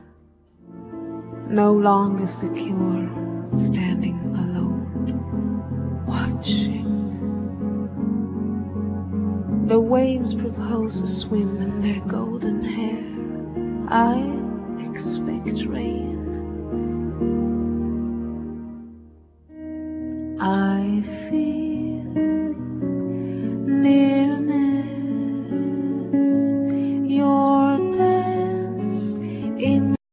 Guitar
Vocals
Soprano saxophone
Drums
Double Bass
Keyboards